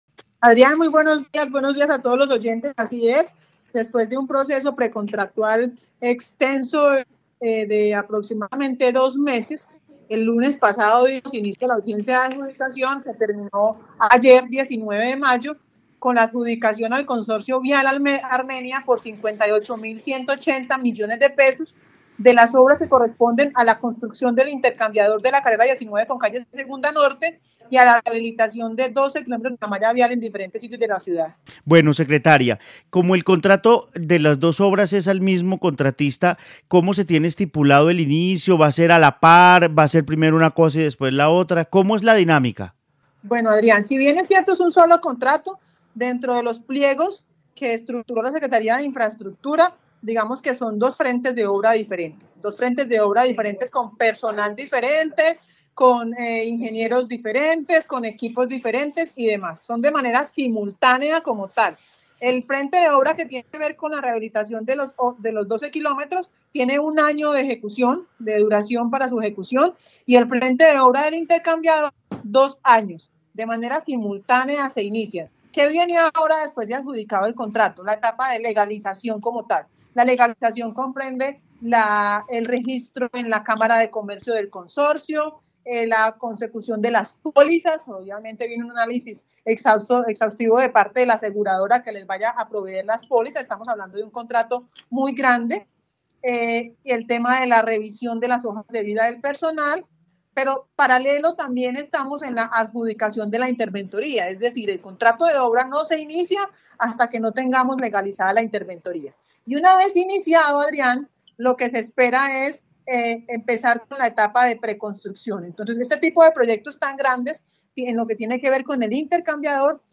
Claudia Arenas, secretaria de infraestructura de Armenia
En el noticiero del mediodía de Caracol Radio Armenia hablamos con Claudia Arenas, secretaría de infraestructura de la ciudad que entregó detalles de los contratos y las obras viales para la capital del Quindío.